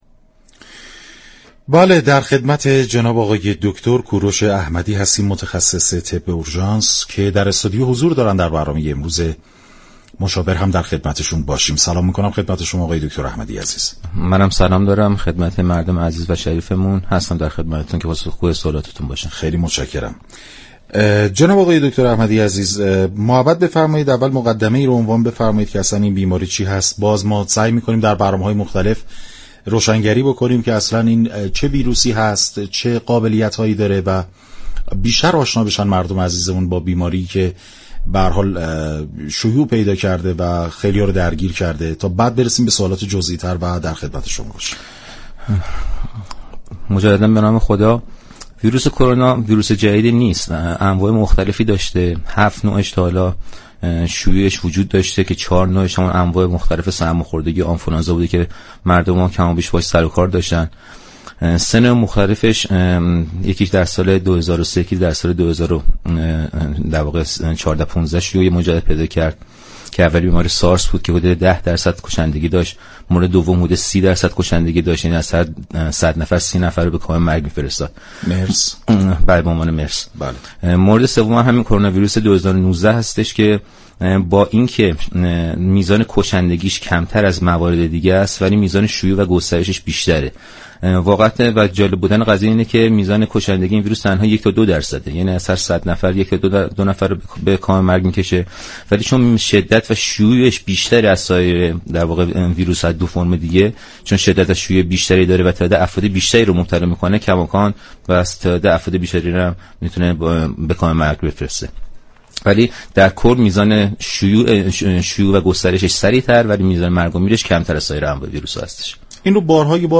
مشاور 8: گفتگو با بهبودیافتگان كرونا
شما می توانید از طریق فایل صوتی پیوست شونده بخشی از برنامه مشاور رادیو ورزش كه شامل صحبت های این متخصص درباره كرونا و پاسخگویی به سوالات عموم است و نیز صحبتهای بهبودیافتگان از این بیماری باشید. برنامه مشاور امروز با محوریت آگاهی رسانی در خصوص بیماری كرونا از شبكه رادیویی ورزش تقدیم شوندگان شد.